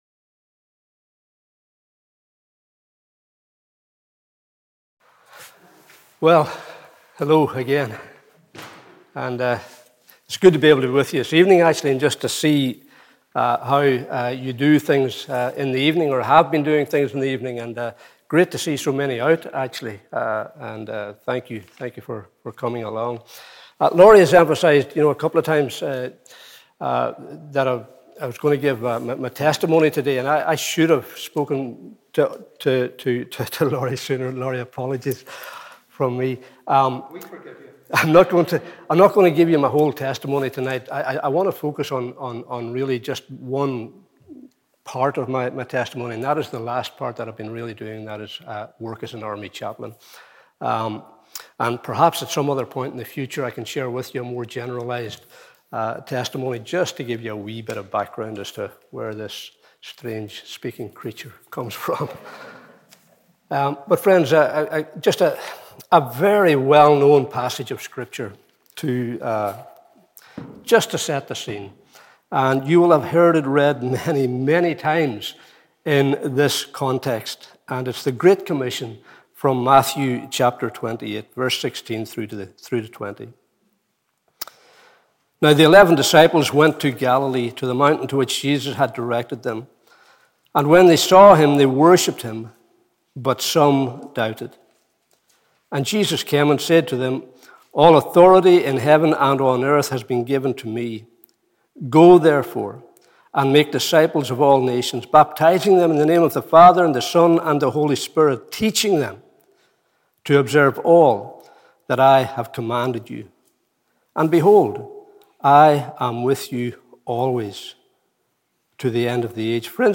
Evening Service 15th August 2021